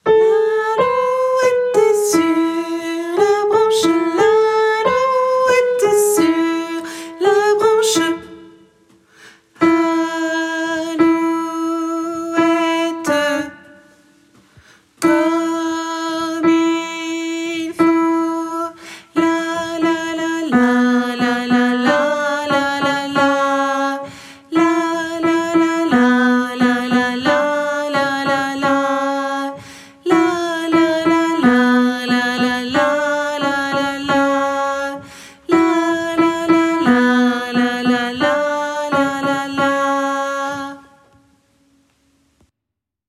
- Œuvre pour choeur à 4 voix mixtes (SATB)
- chanson populaire de Lorraine
MP3 versions chantées
Alto